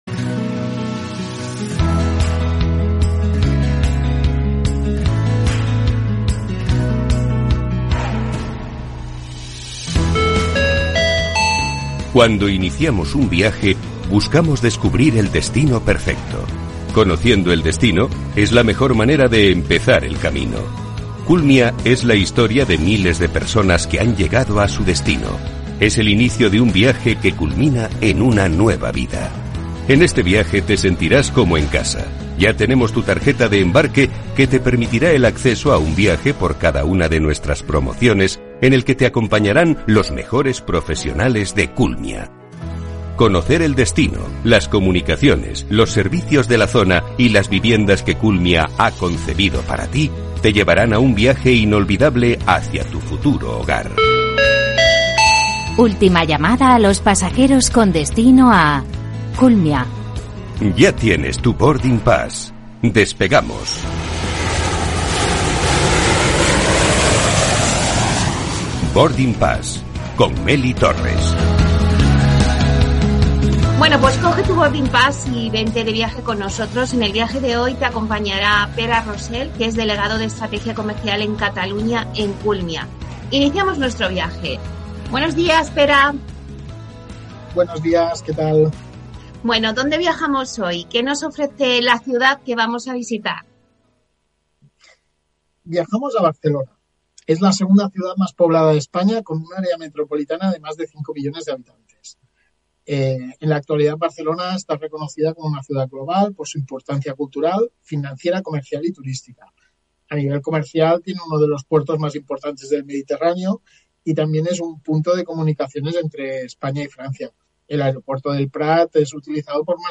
ha sido el encargado de participar en un nuevo episodio de Boarding Pass en la sección de Capital Radio